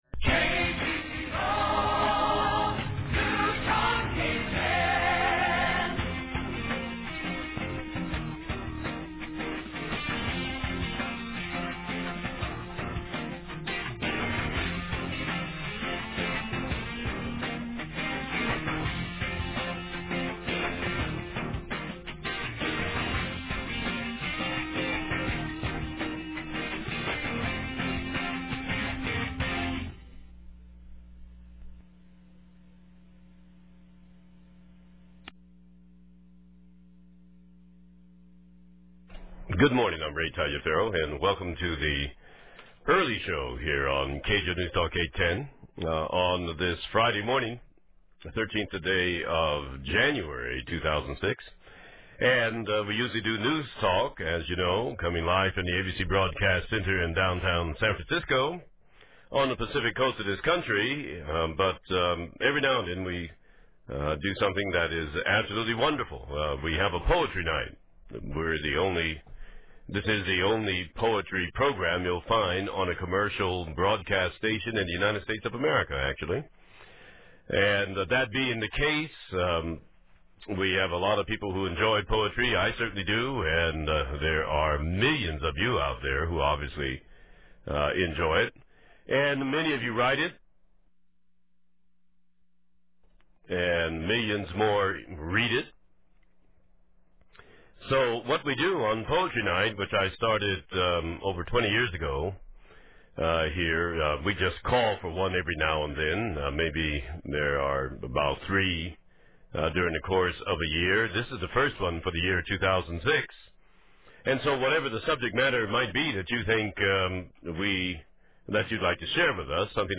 Four hours of powerful poetry on the program of socio-political giant Ray Taliaferro on KGO San Francisco.